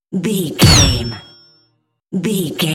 Dramatic hit deep metal clicnk
Sound Effects
Atonal
heavy
dark
aggressive